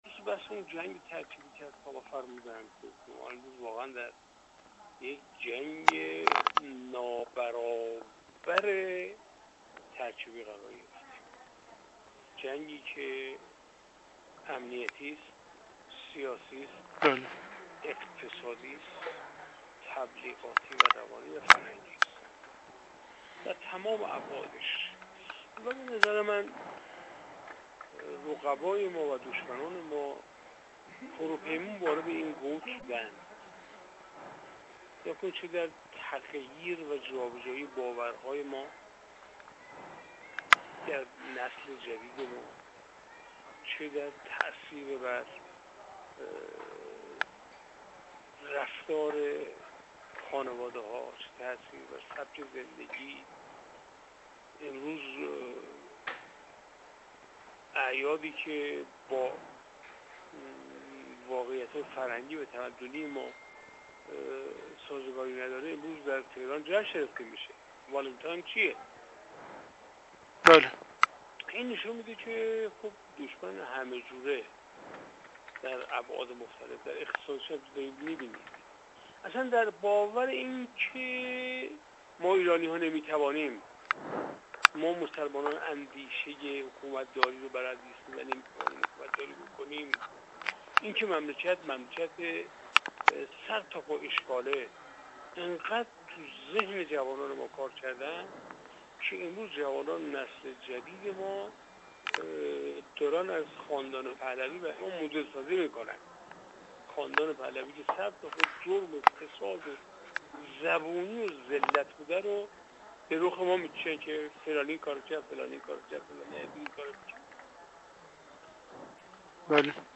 منصور حقیقت‌پور، کارشناس مسائل سیاسی